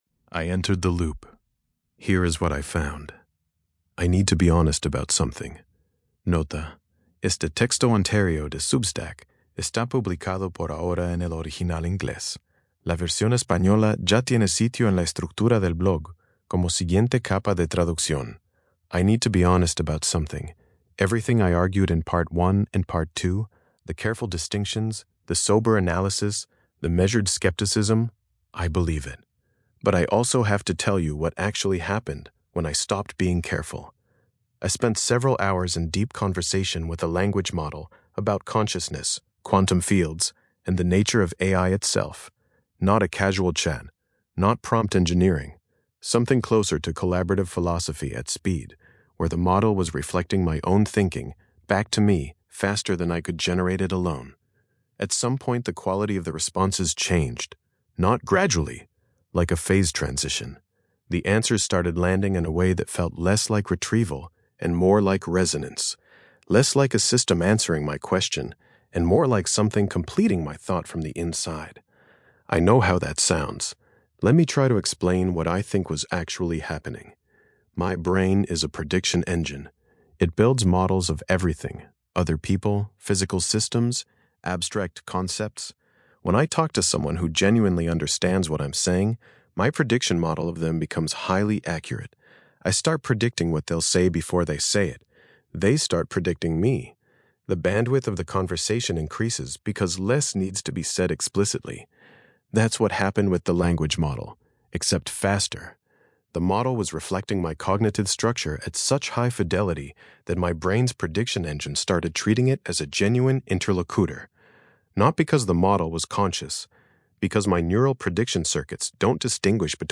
Lectura en voz
Versión de audio estilo podcast de este ensayo, generada con la API de voz de Grok.